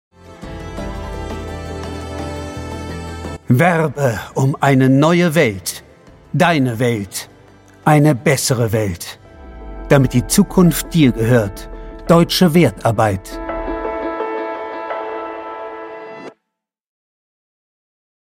Gelernter Schauspieler. Glaubwürdig, ausdrucksstark, überzeugend, stimmlich variabel. Dialektfreie Mittellage, weich & samtig.
Sprechprobe: Werbung (Muttersprache):
Trained german actor. Credible, expressive, convincing, vocally variable. Dialect-free mid-range, soft & velvety.